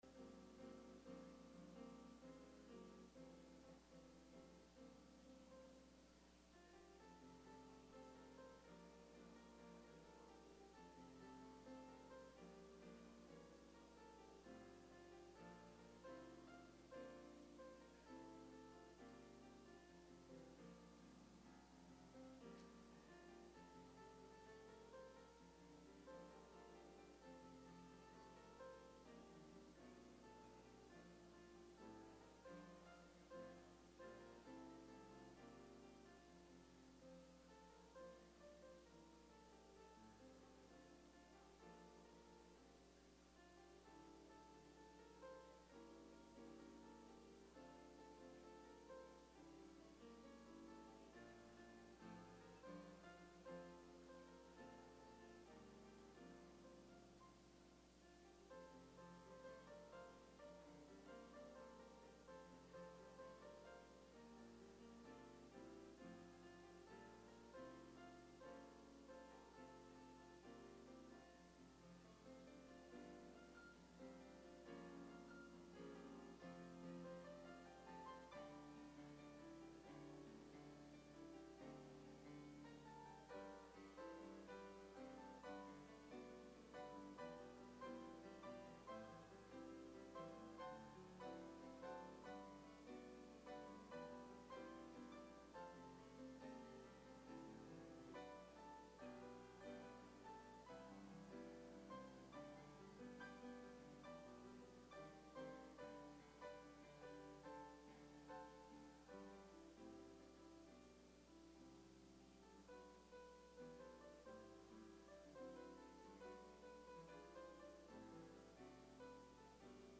Spencer Baptist Church Sermons